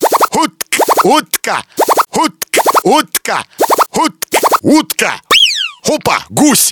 UTKA.mp3